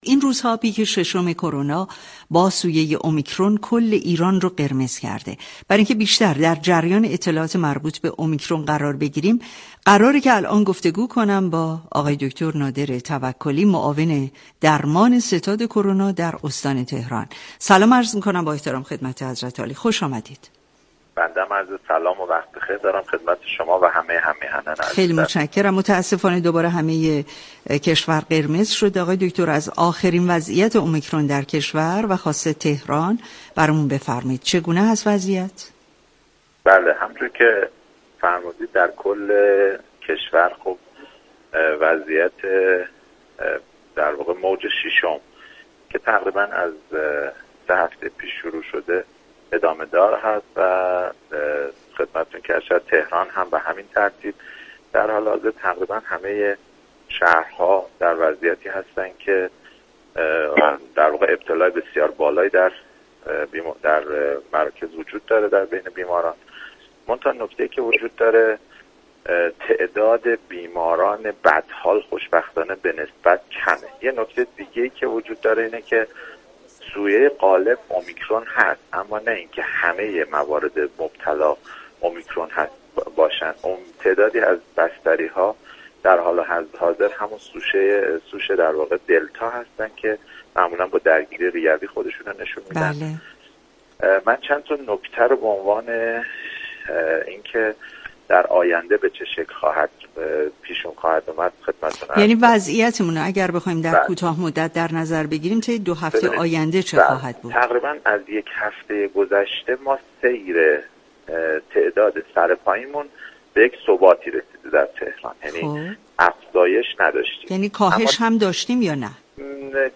به گزارش پایگاه اطلاع رسانی رادیو تهران، دكتر نادر توكلی معاون درمان ستاد كرونای استان تهران در گفتگو با برنامه تهران ما سلامت اول اسفندماه درباره آخرین وضعیت اُمیكرون در كشور گفت: موج ششم از سه هفته پیش آغاز شده و همچنان در تمامی كشور ادامه دارد.